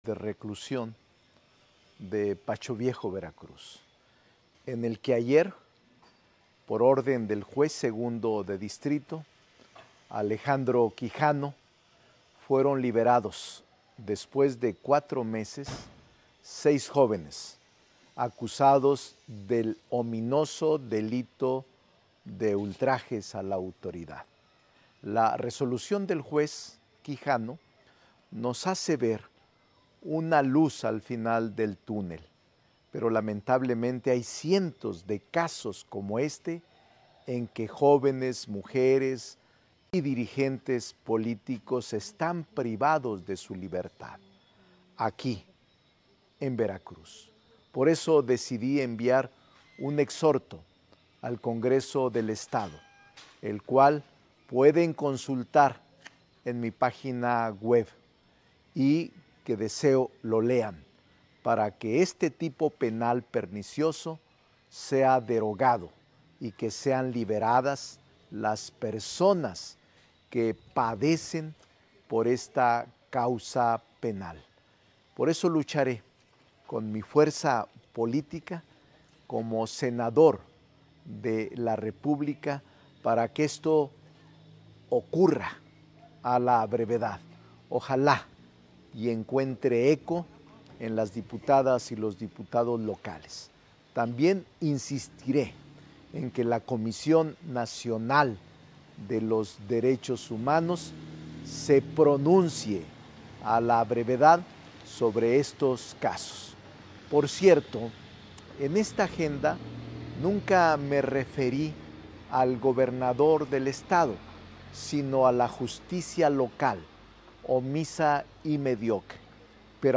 Este domingo, desde el penal de Pacho Viejo, el senador Ricardo Monreal Ávila aclaró que no defiende a delincuentes como lo acusó el gobernador Cuitláhuac García Jiménez.